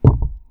big-thud2.wav